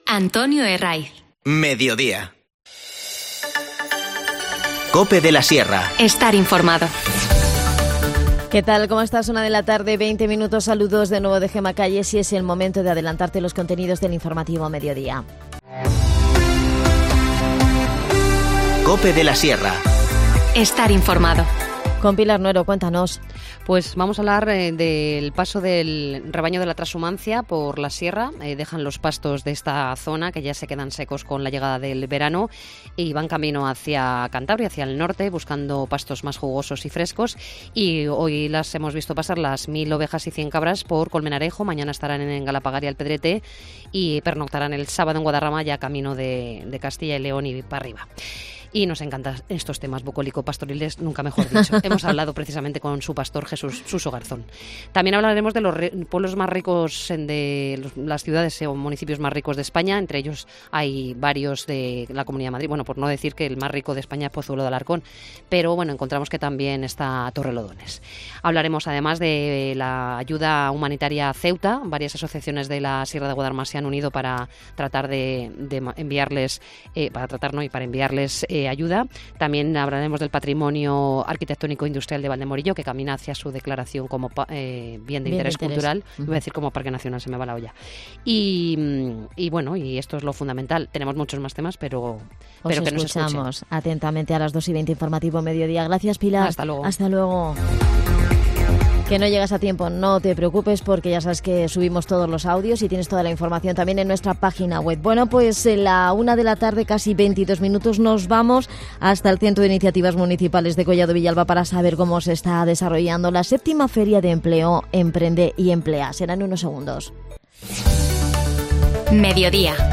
Desde la Feria nos ha dado todos los detalles el concejal de Desarrollo Local, Adan Martínez, en el espacio 'Collado Villalba, Capital de la Sierra'. También hablamos con un empresario que ha acudido al evento en busca de profesionales para su negocio.